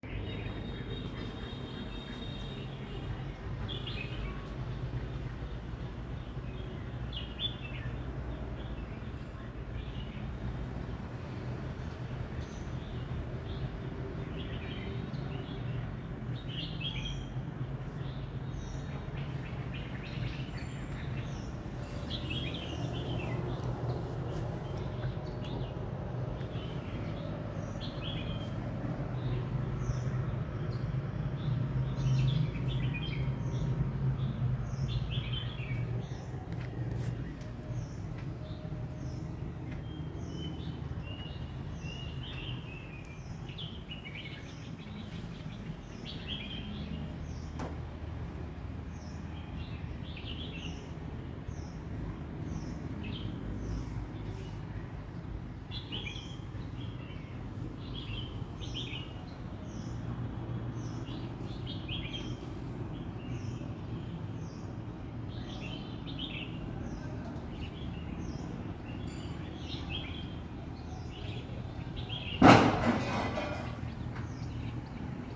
그래도 어쩜 저리 앙증맞게 지저귀는지, 눈 뜨지 않고는 배길 수 없다. 호이안 어디서도 이처럼 새뜻한 소리는 듣지 못했다. 배부른 고양이 새끼 냄새 맡아보듯 새소리를 따라 발코니로 나갔다.
새소리.m4a